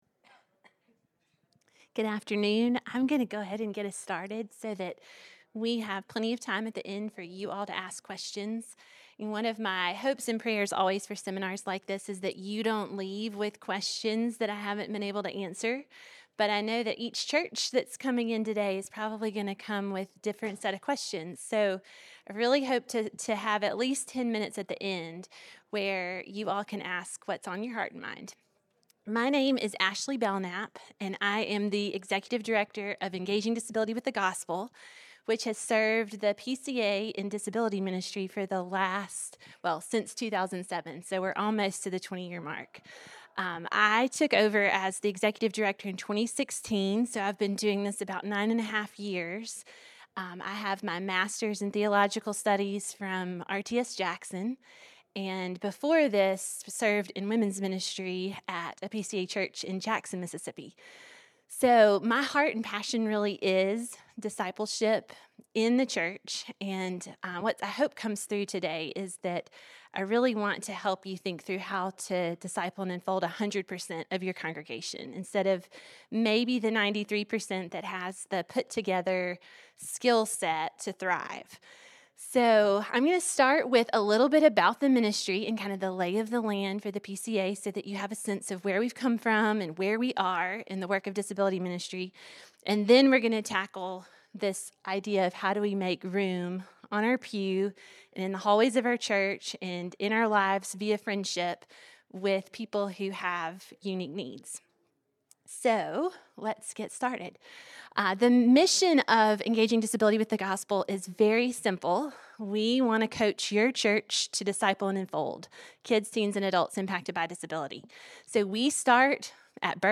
This seminar will challenge and equip you to extend discipleship and care to all members of the body, including children, teens, and adults impacted by disability.